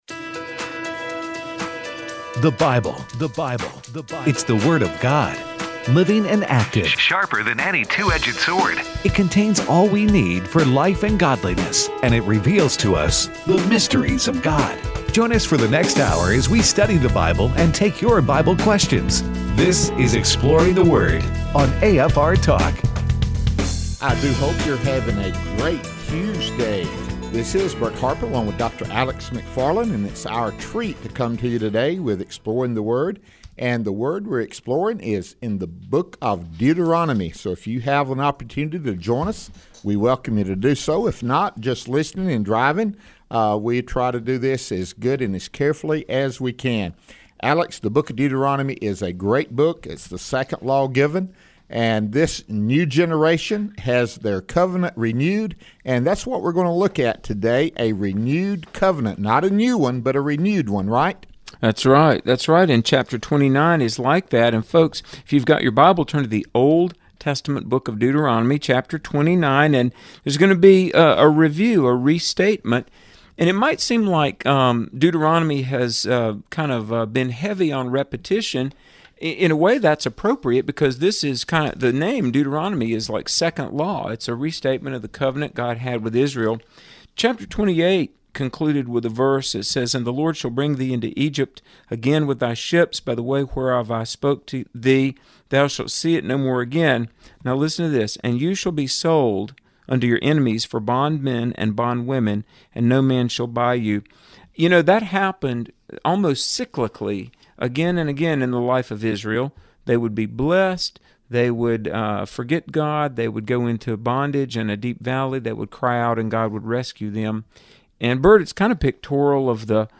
and then they take your phone calls in the last segment.